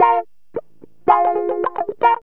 GTR 4 A#M110.wav